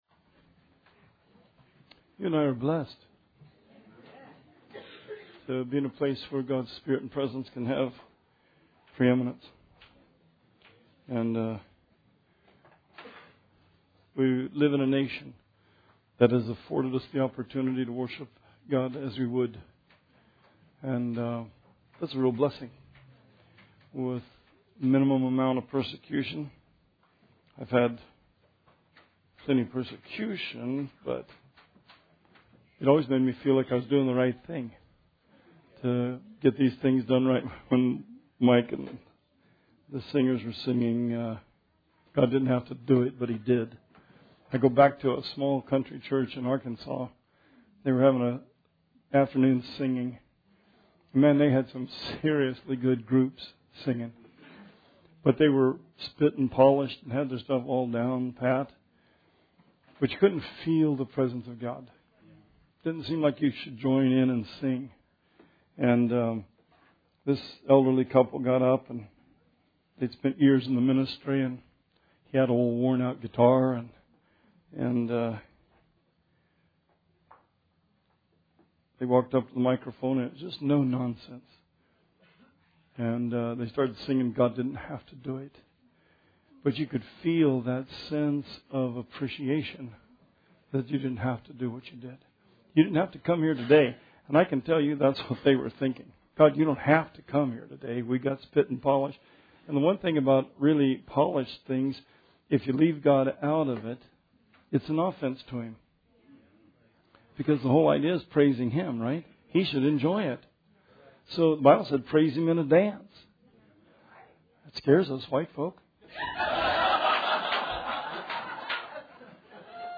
Sermon 10/30/16